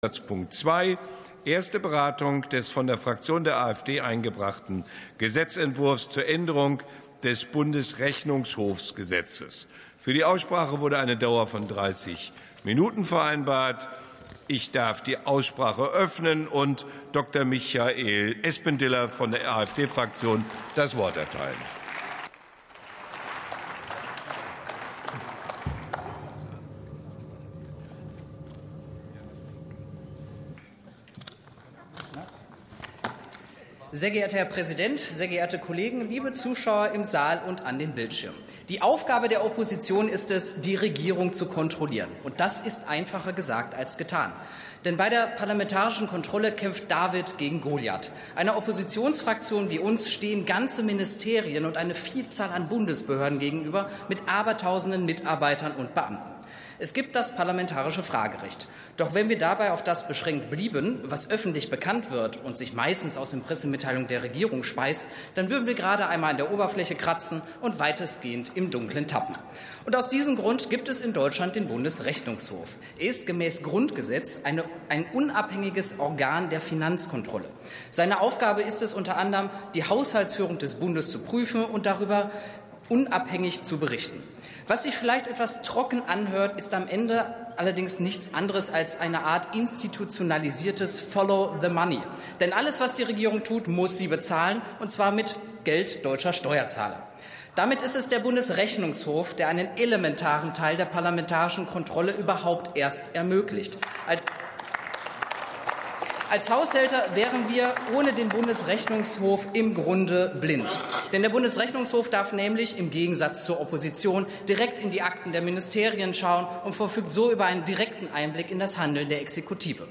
61. Sitzung vom 04.03.2026. TOP ZP 2: Unabhängigkeit des Bundesrechnungshofes ~ Plenarsitzungen - Audio Podcasts Podcast